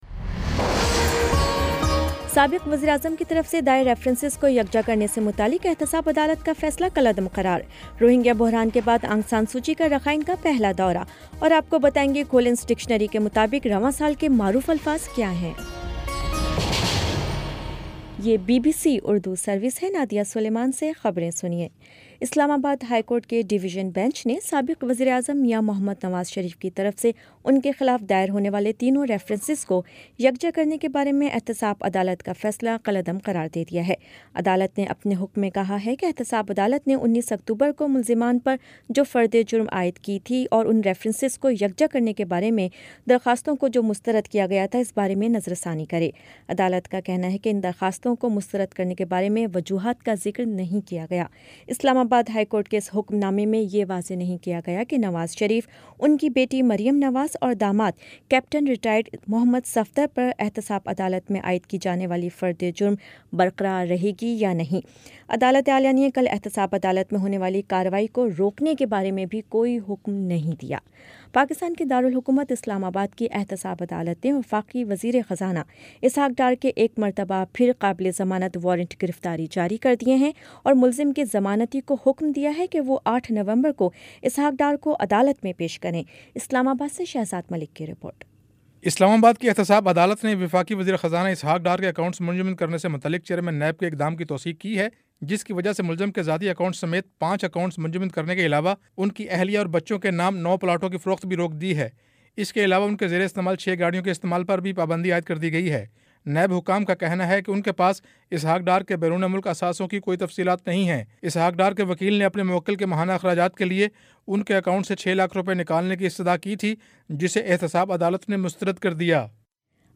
نومبر 02 : شام پانچ بجے کا نیوز بُلیٹن